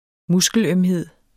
Udtale [ ˈmusgəlˌœmheðˀ ]